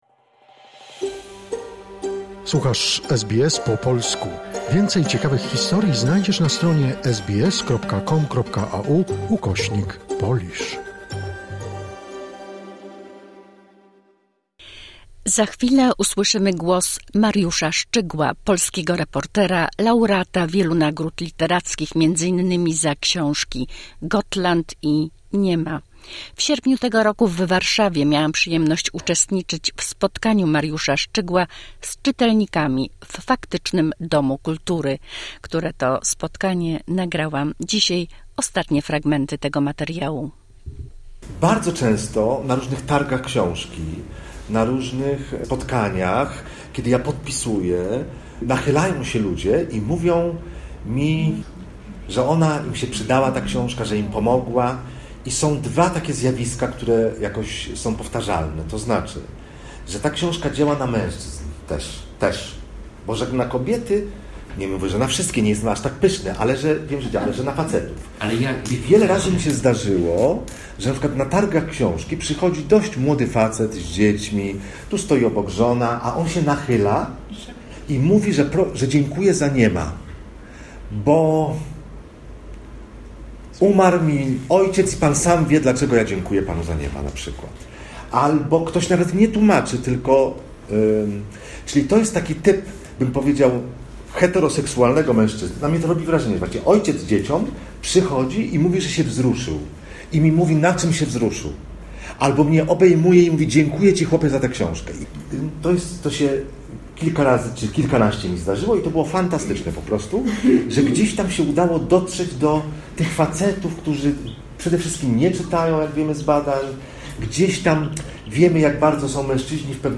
Fragmenty nagrania ze spotkania z autorem w Faktycznym Domu Kultury w Warszawie w sierpniu 2024.